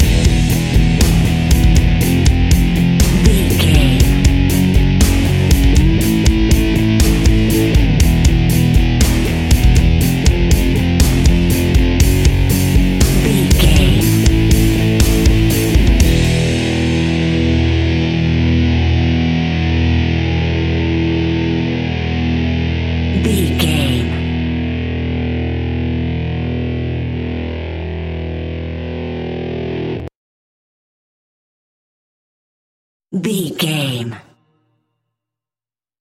Epic / Action
Ionian/Major
hard rock
heavy metal
distortion
rock guitars
Rock Bass
Rock Drums
heavy drums
distorted guitars
hammond organ